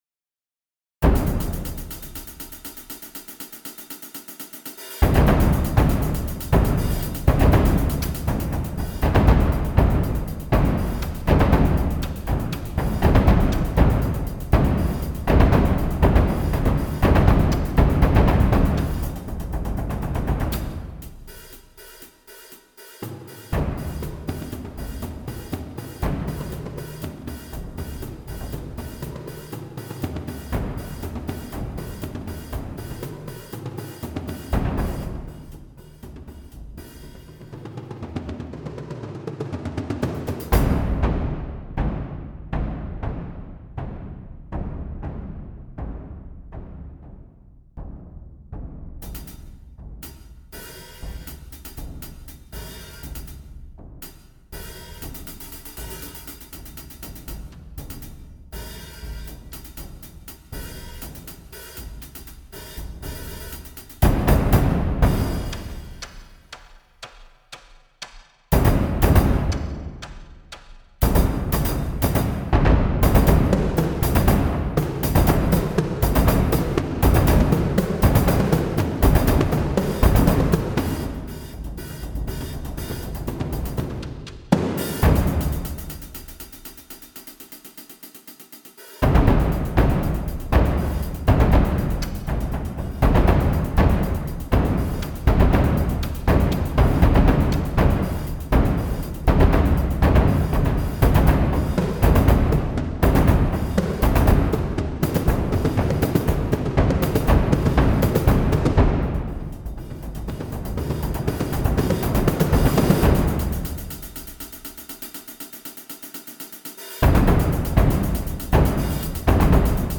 Genre: Percussion Ensemble
Player 1: Shared Bass Drum, High Tom
Player 2: Shared Bass Drum, Medium Tom
Player 3: Shared Bass Drum, Low Tom
Player 4: Hi-Hat